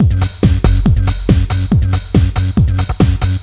zar_beat.wav